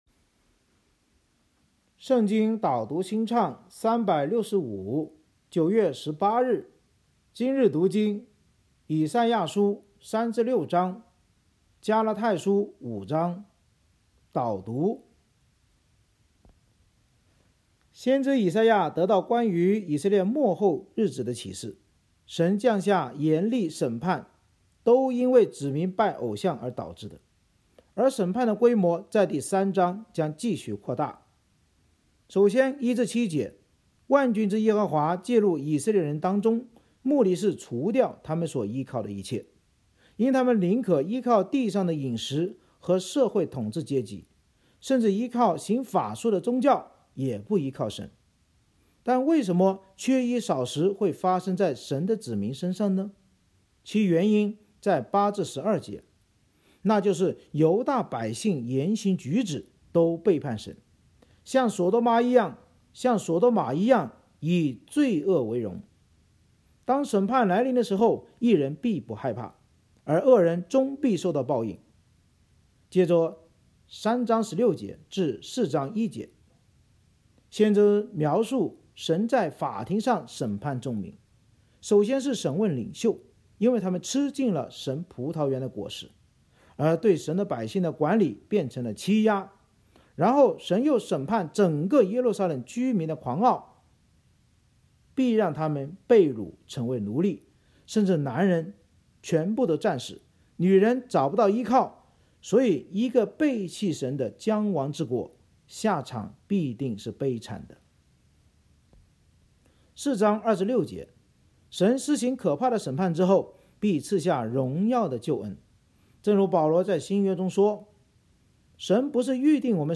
圣经导读&经文朗读 – 09月18日（音频+文字+新歌）